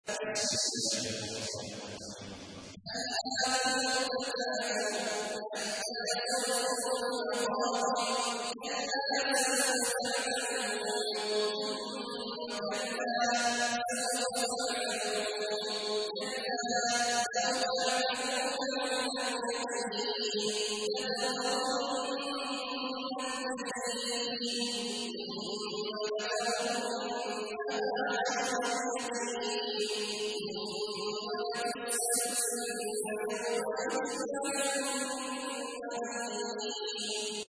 تحميل : 102. سورة التكاثر / القارئ عبد الله عواد الجهني / القرآن الكريم / موقع يا حسين